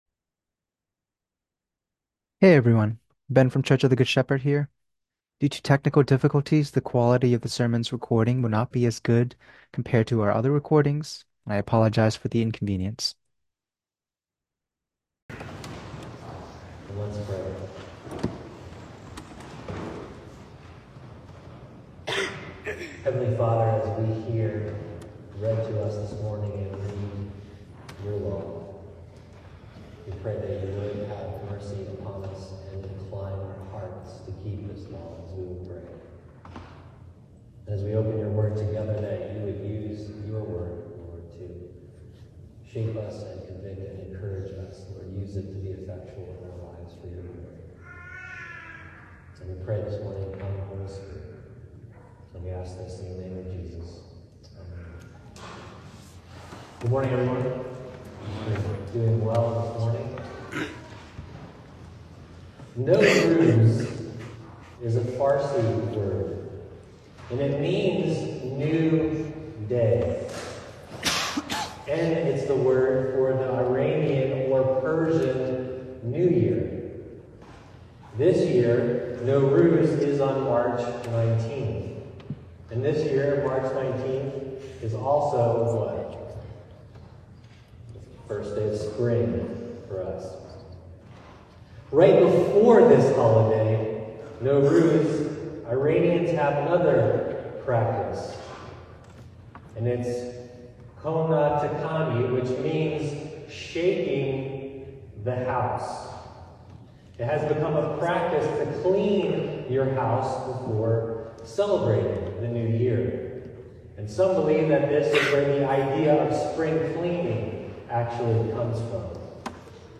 A sermon on the Third Sunday of Lent